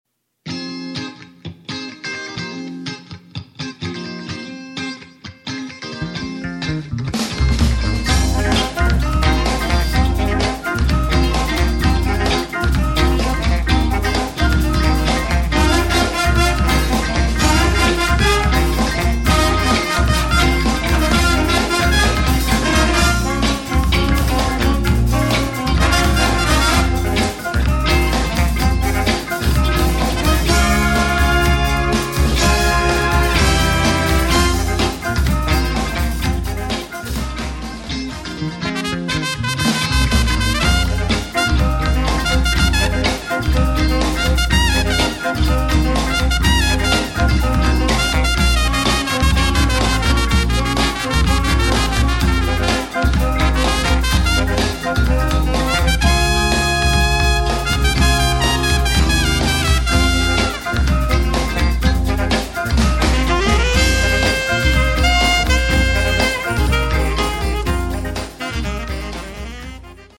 Street Band